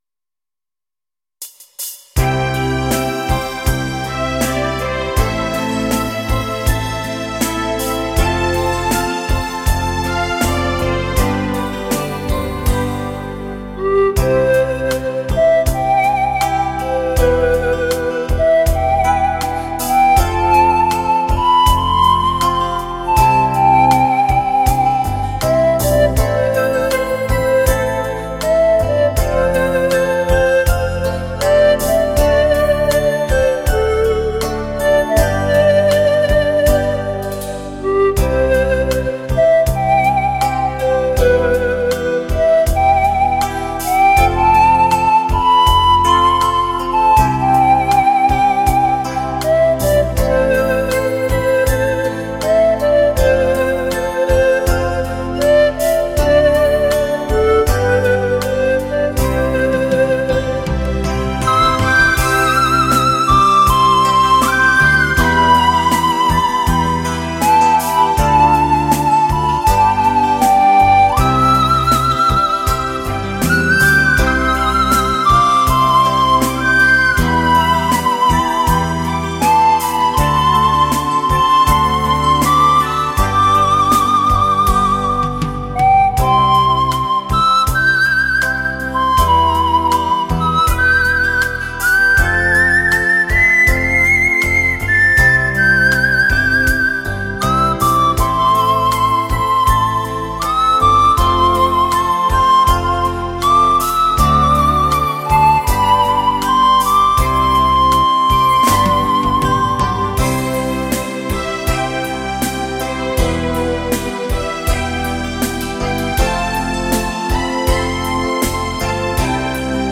Panpipes Music
重现 黑胶唱片 音质纯正 音色圆润
随着熟悉的旋律，轻柔的音乐，怀念年少的情怀。
排箫的声音，清亮中含着暗哑，悠扬中带着迷朦，空旷中透出冥思，深情而略带忧伤。